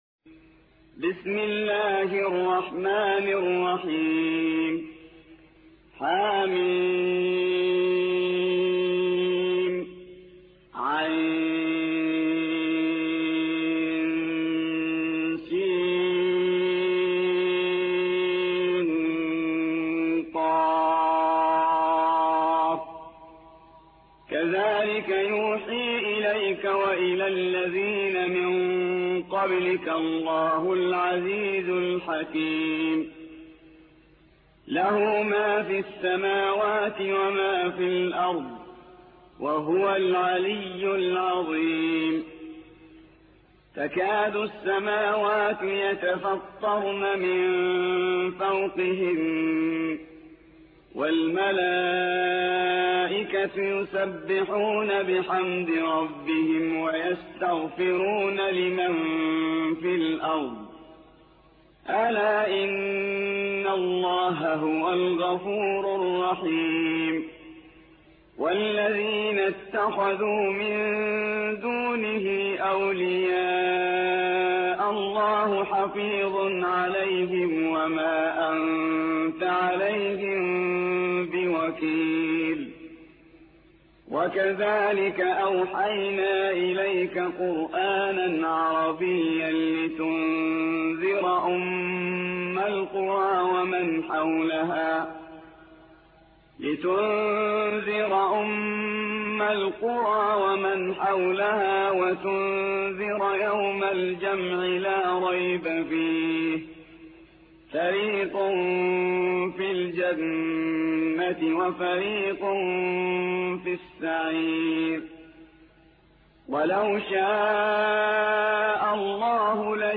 42. سورة الشورى / القارئ